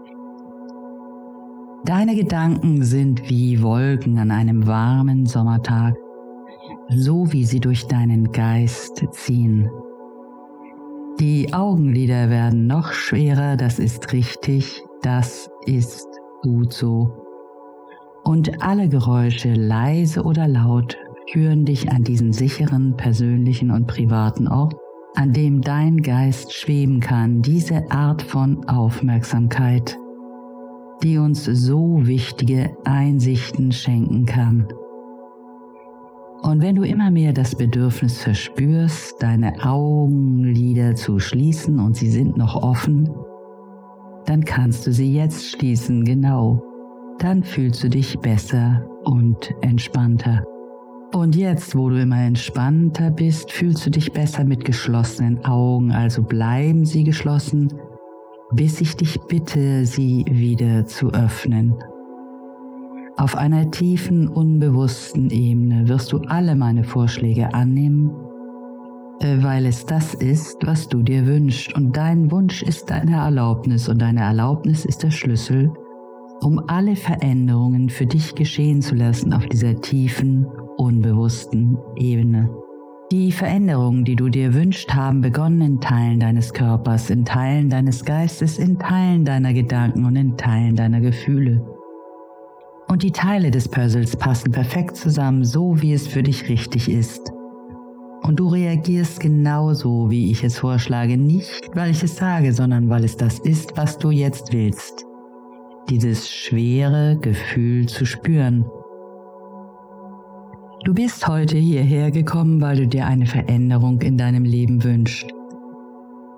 • Musik: Ja;